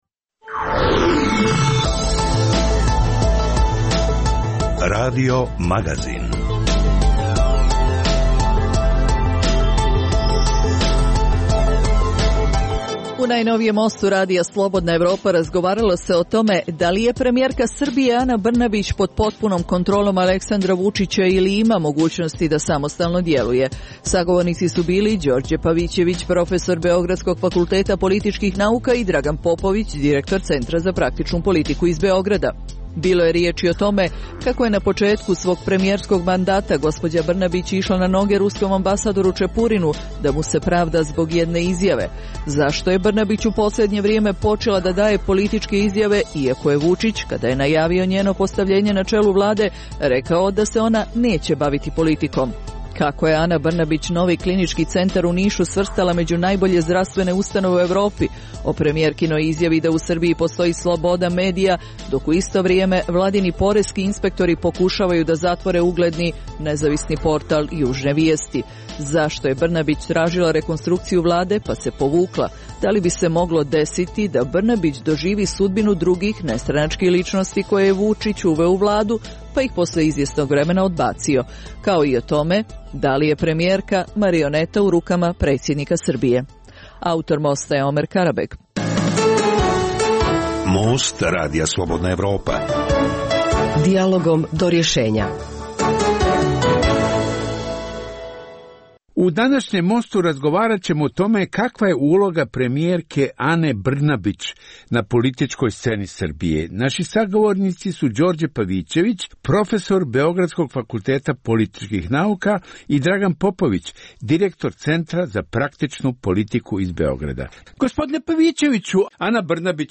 Dijaloška emisija o politici, ekonomiji i kulturi.